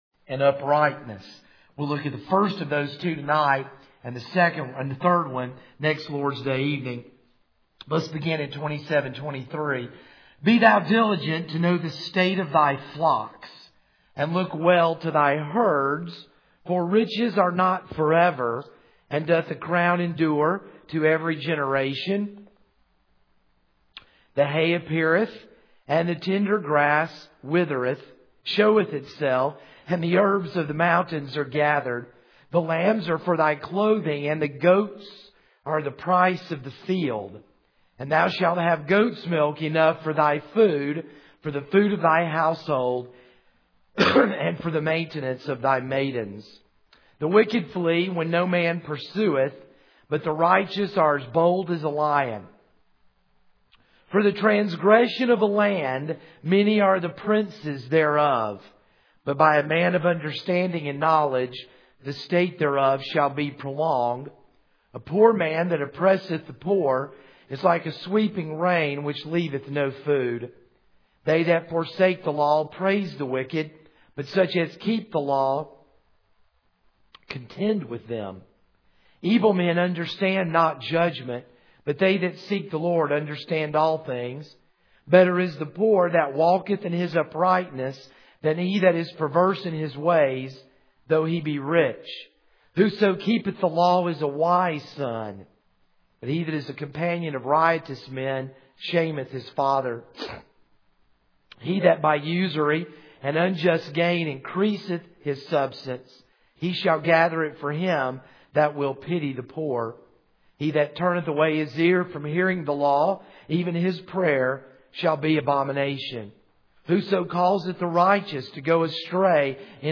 This is a sermon on Proverbs 27:23-28:28.